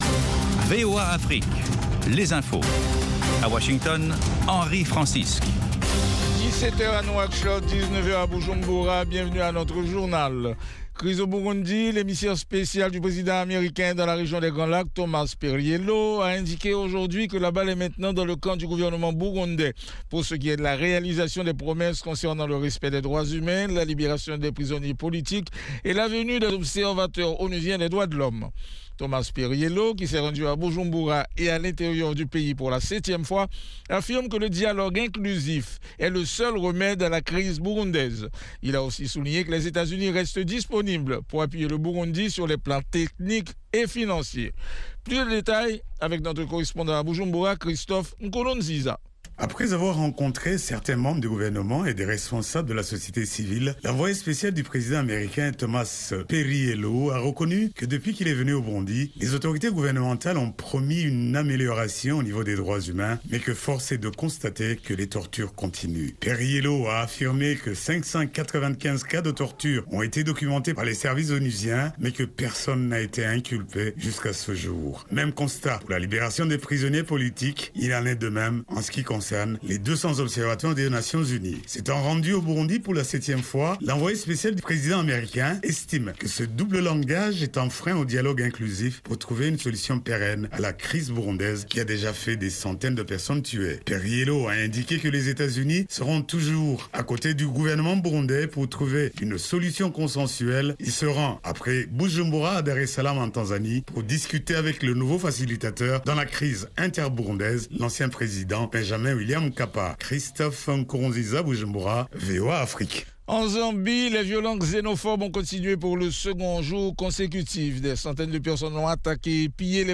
10 min News French